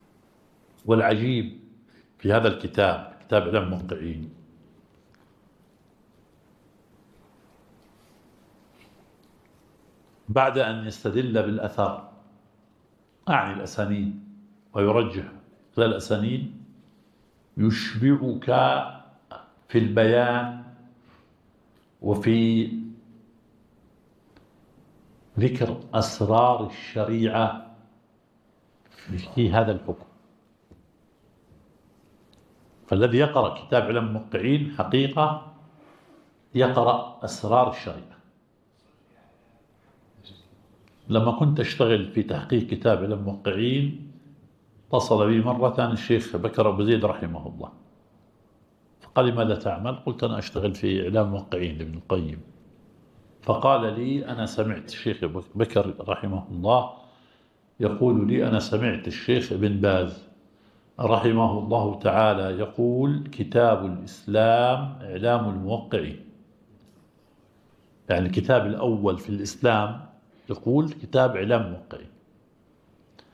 الدرس الخامس عشر – شرح مبحث العام والخاص في أصول الفقه – فضيلة الشيخ مشهور بن حسن آل سلمان.